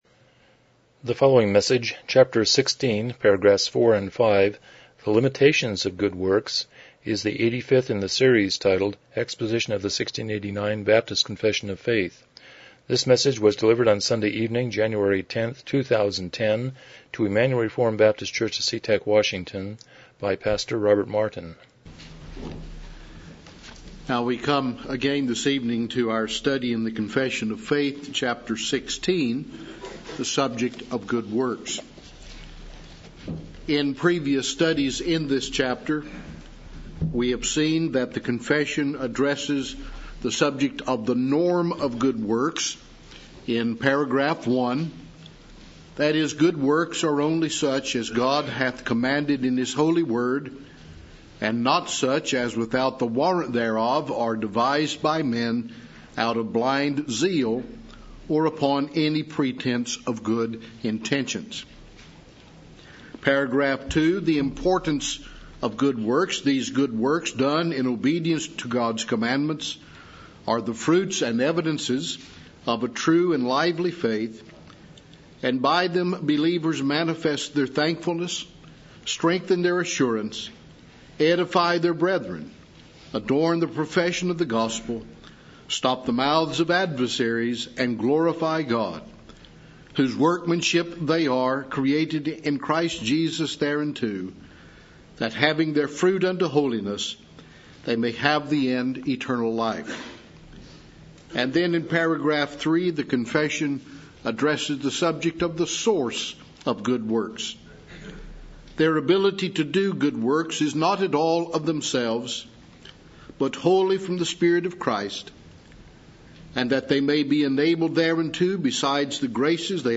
1689 Confession of Faith Service Type: Evening Worship « 100 Romans 8:17b